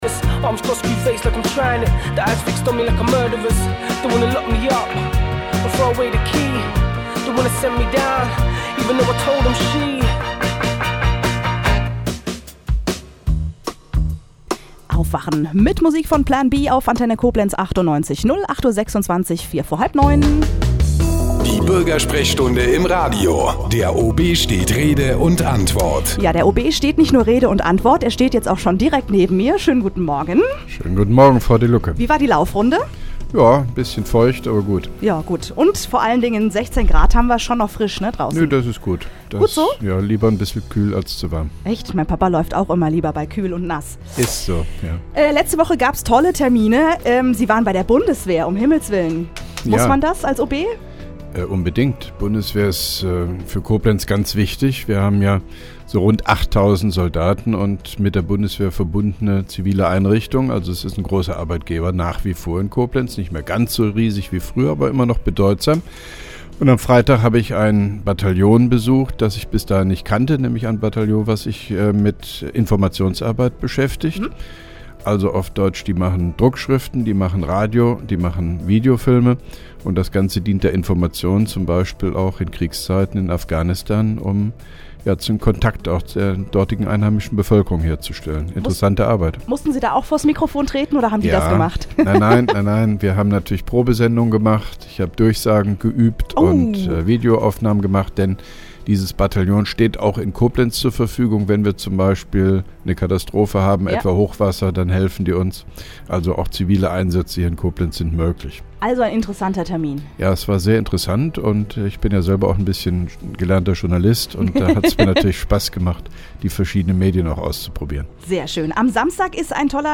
(1) Koblenzer OB Radio-Bürgersprechstunde 14.09.2010
Interviews/Gespräche